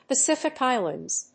アクセントPacífic Íslands, the Trúst Térritory of the